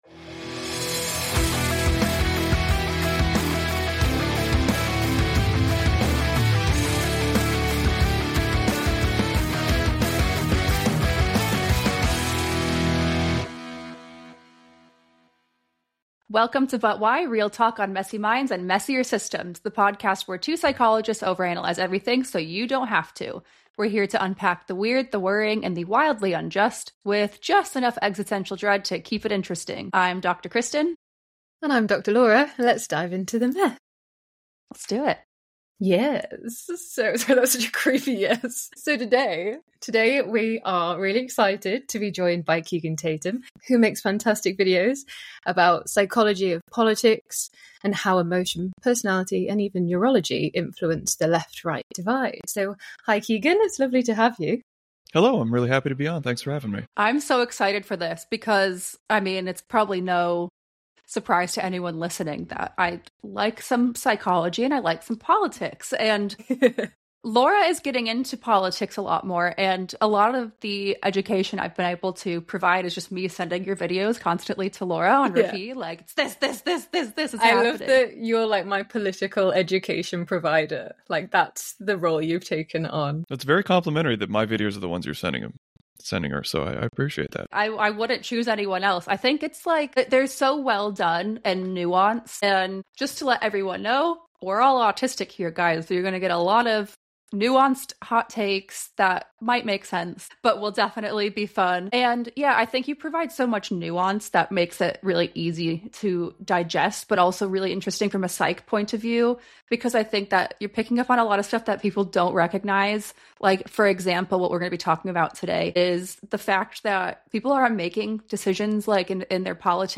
This episode is packed with personal stories, critical theory, and darkly funny tangents